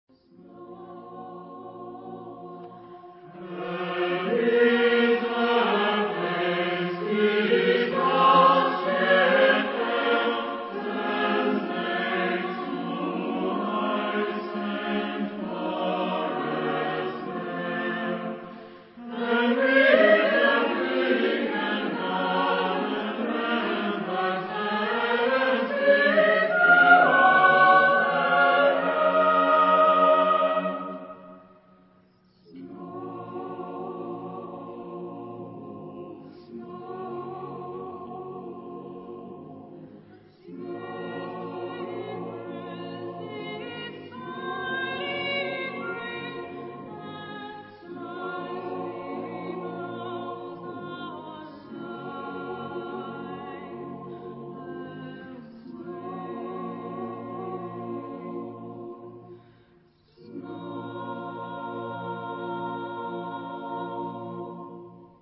Genre-Style-Form: Suite ; Secular ; Contemporary
Type of Choir: SATB  (4 mixed voices )
Tonality: C major ; G major ; B minor